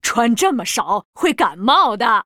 文件 文件历史 文件用途 全域文件用途 Balena_fw_02.ogg （Ogg Vorbis声音文件，长度2.2秒，100 kbps，文件大小：27 KB） 源地址:游戏语音 文件历史 点击某个日期/时间查看对应时刻的文件。